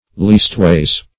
Leastways \Least"ways`\, Leastwise \Least"wise`\, adv.